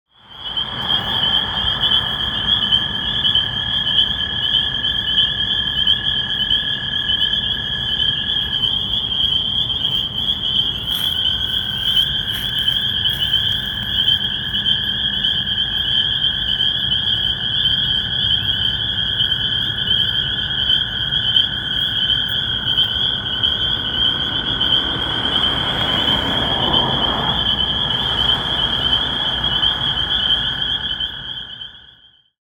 Ahhhh, the oh so sweet sounds of the Spring Peepers.
Looking for love in all the right bogs.